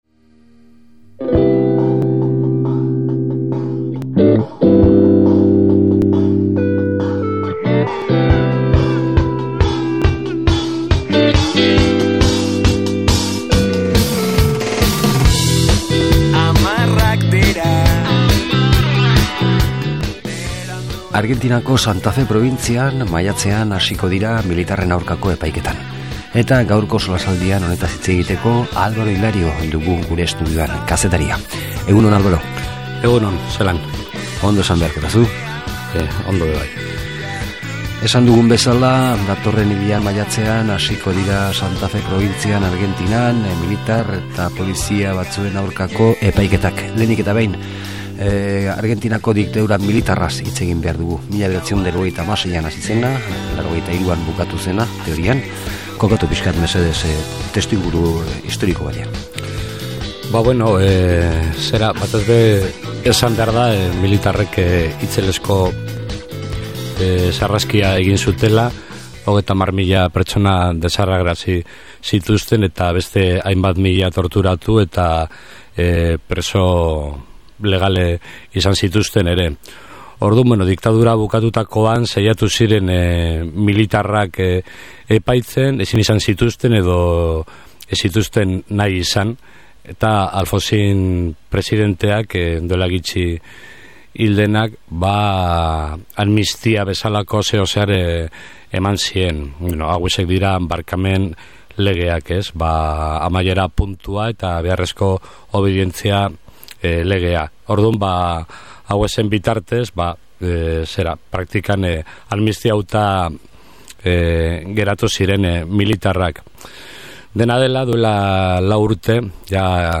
SOLASALDIA: Argentinako militar batzuen epaiketa
solasaldia